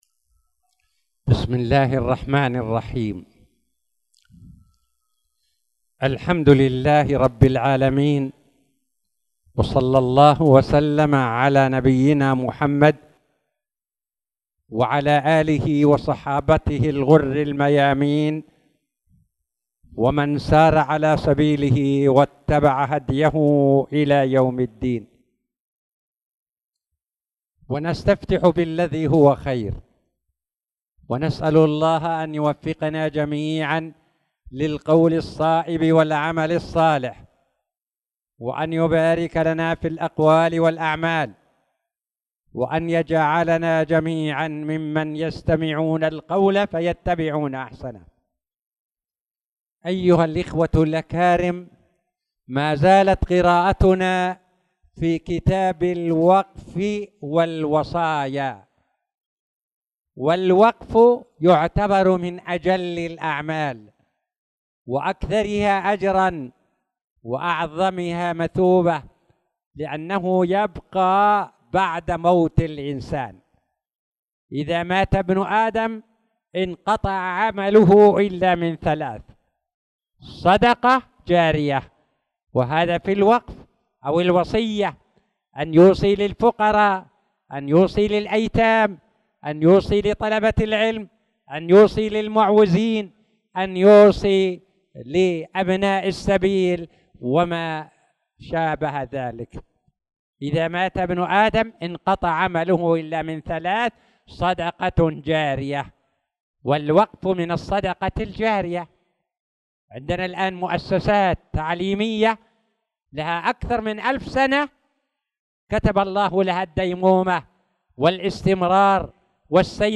تاريخ النشر ١٥ شعبان ١٤٣٧ هـ المكان: المسجد الحرام الشيخ